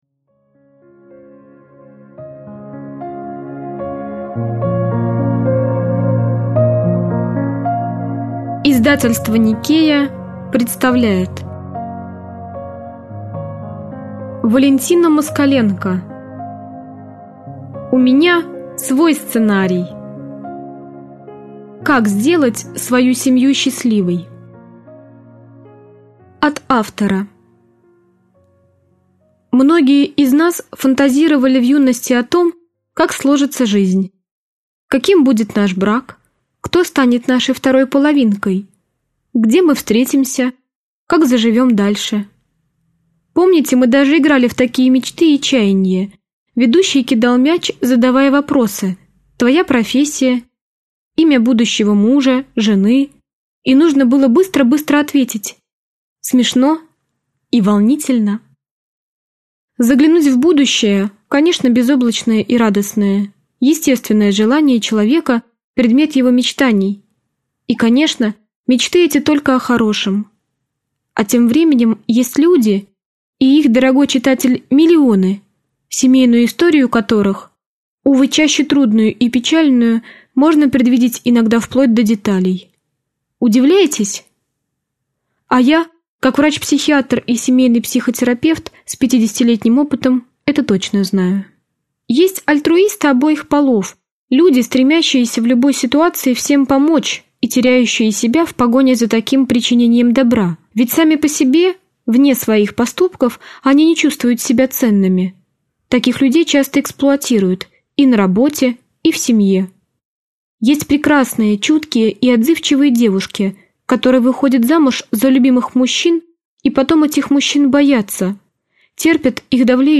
Аудиокнига У меня свой сценарий. Как сделать свою семью счастливой | Библиотека аудиокниг
Прослушать и бесплатно скачать фрагмент аудиокниги